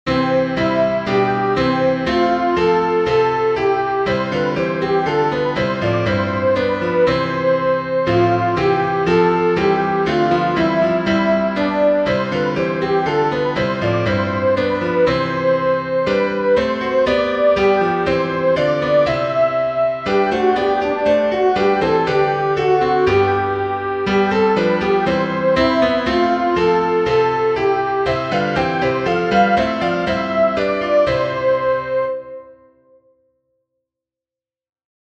Descant
easter_jesus_christ_is_risen_today-descant.mp3